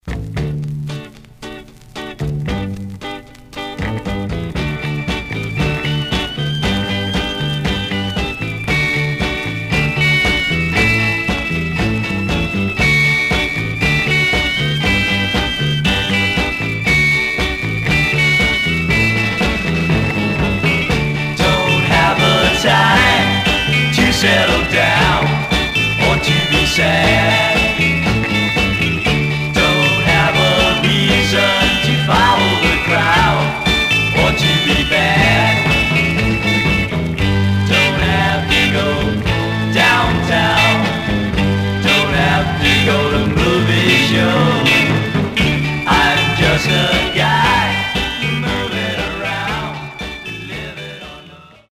Some surface noise/wear Stereo/mono Mono
Garage, 60's Punk